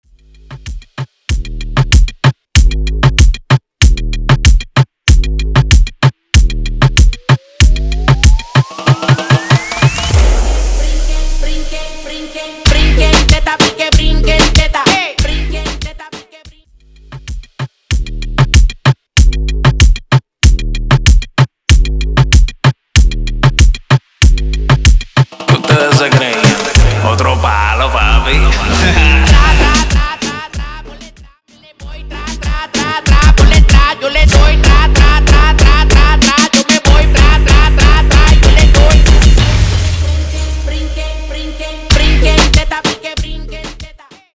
Intro Dirty, Hype Dirty